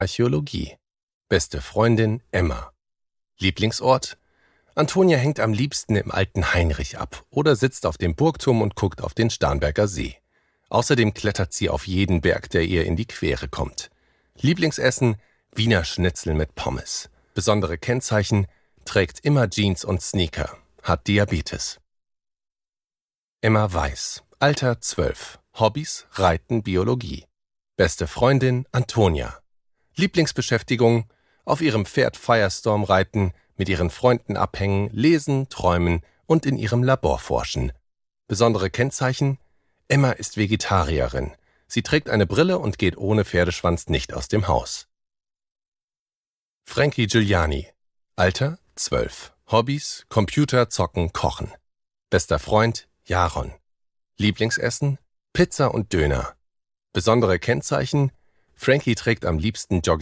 Die verborgene Gruft und das Ende aller Tränen - Hörbuch
Hörbücher für Kinder/Jugendliche